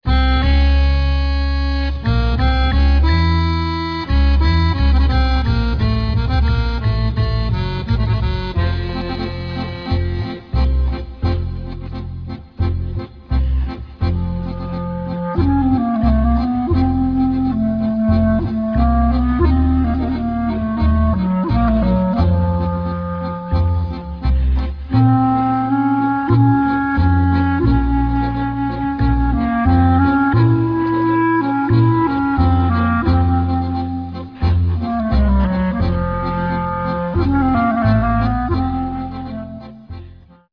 klarnet clarinet
akordeon accordion
kontrabas double-bass